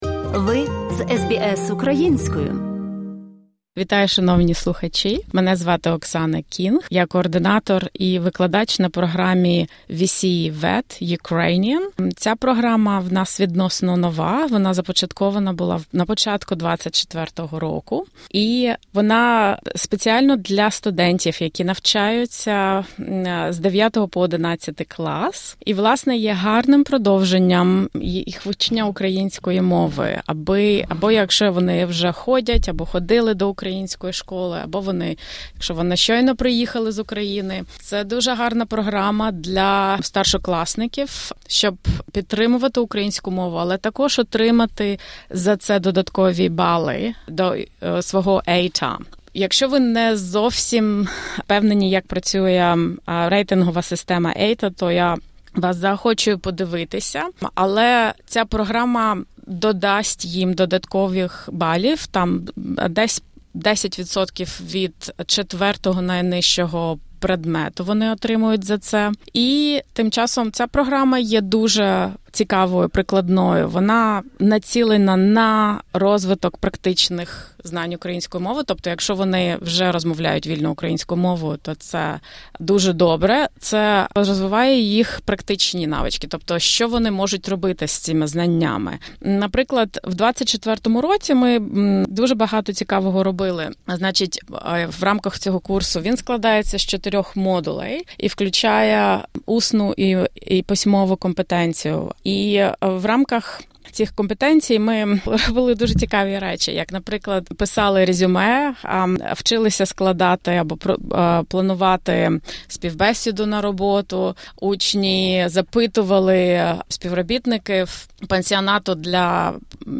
В інтерв’ю також розглядається роль програм вивчення мов, таких як VCE VET Ukrainian, у сприянні міжкультурному взаєморозумінню та підготовці студентів до все більш глобалізованого світу.